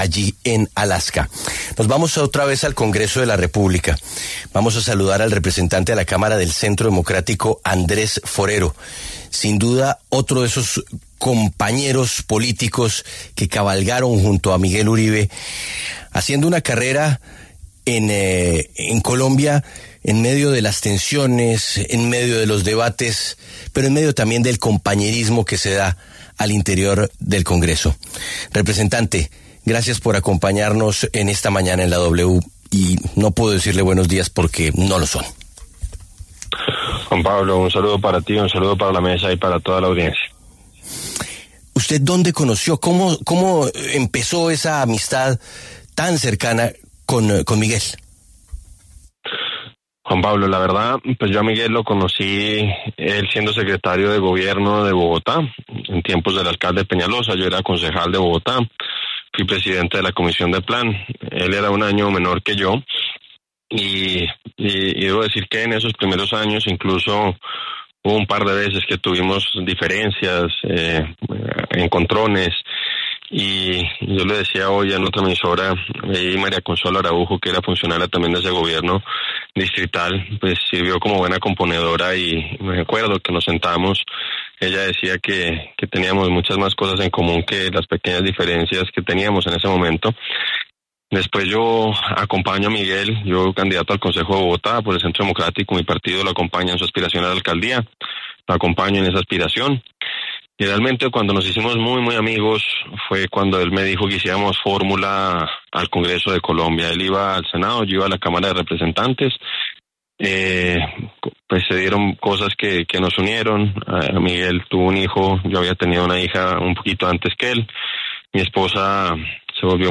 El representante Andrés Forero, colega y amigo de Miguel Uribe, pasó por los micrófonos de La W y lamentó la muerte del senador y precandidato presidencial luego de haber sido víctima de un atentado el pasado 7 de junio.